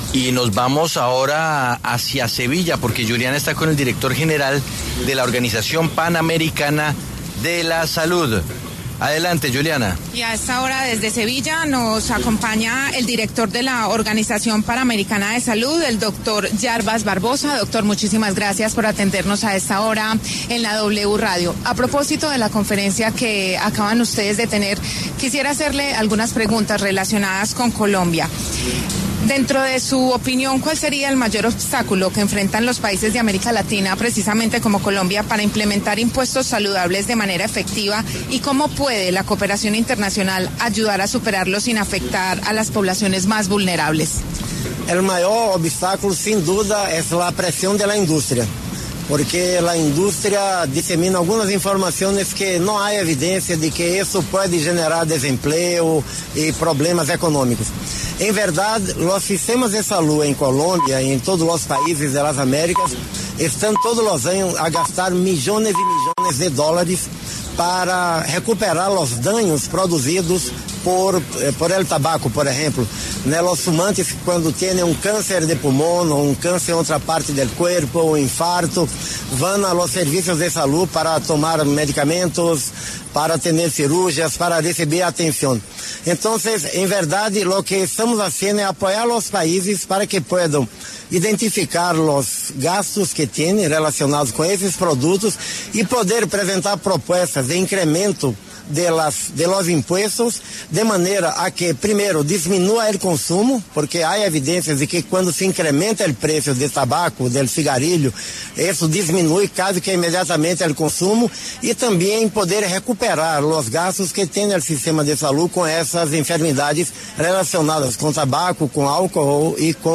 Jarbas Barbosa, director de la Organización Panamericana de Salud, habló para los micrófonos de La W desde la Conferencia de la ONU en Sevilla, España.